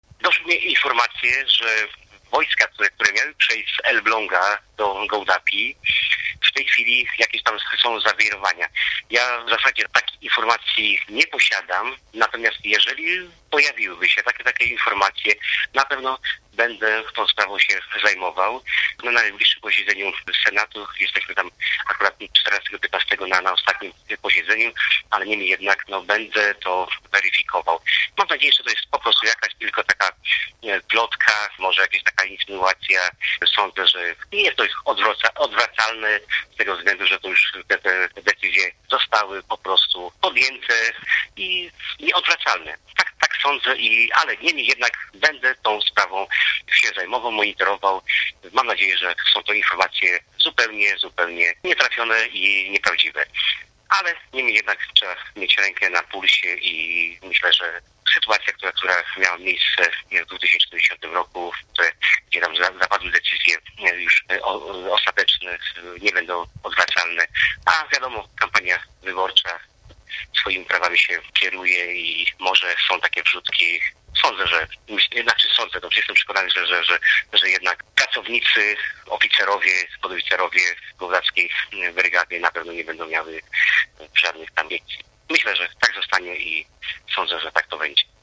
mówi senator Marek Konopka